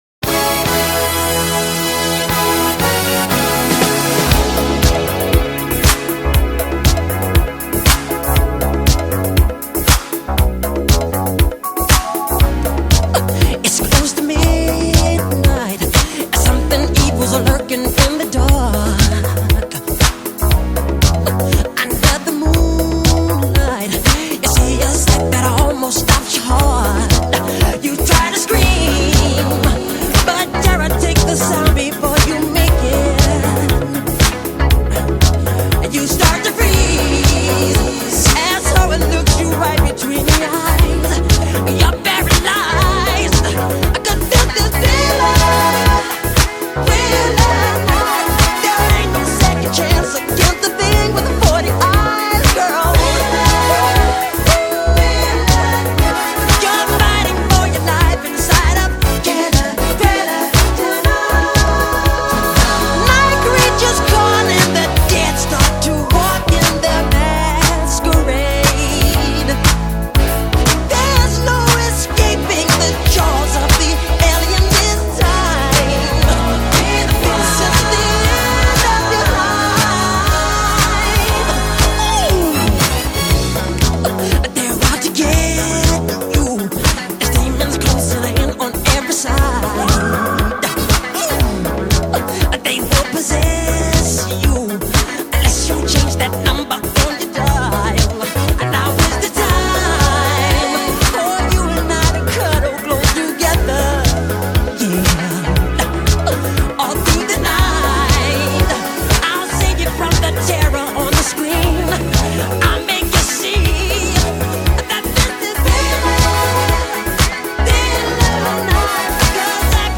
BPM106-125
Audio QualityCut From Video